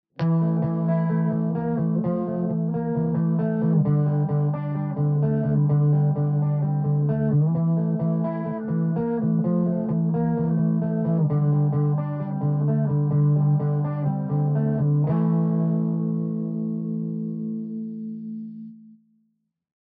Пример звука Revolver (drive-chorus)
Записано в линию (SB Live5.1)              Без дополнительной обработки
Записано на гитаре Fender Squier